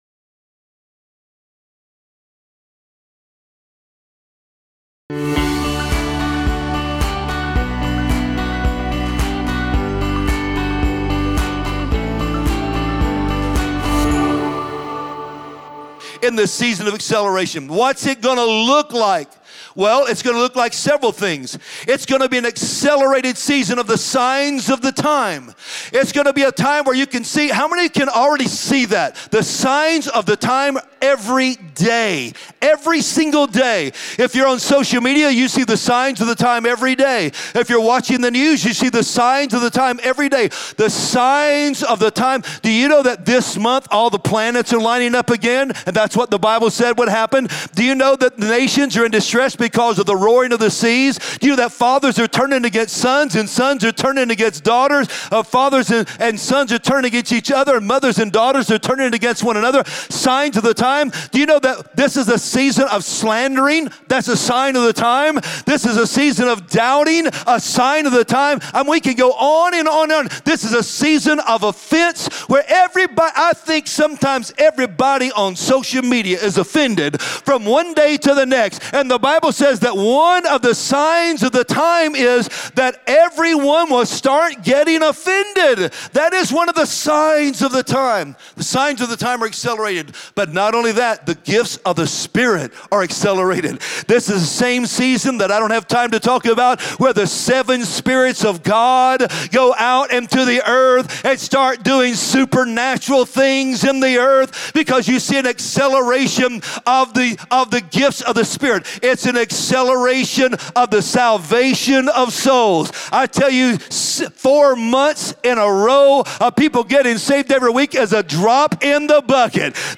Join us this week for the sermon “The Final Bloom.”